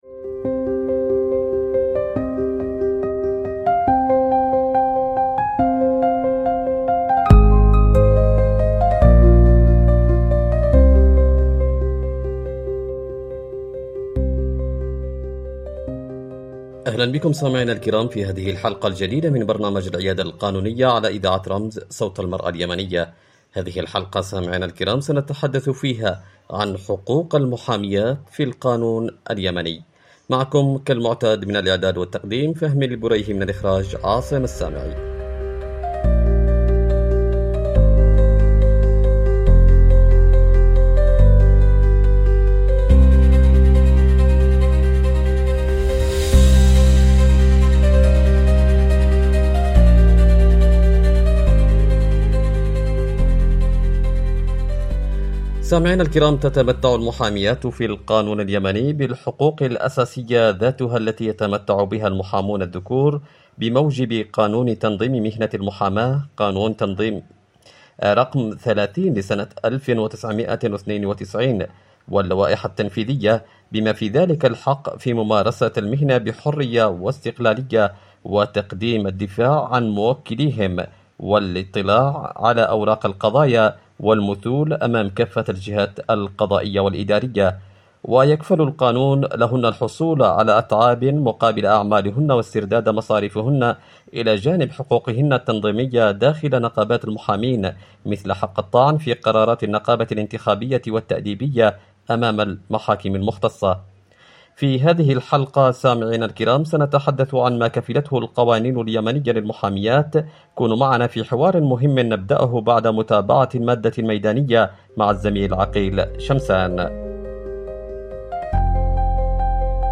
عبر إذاعة رمز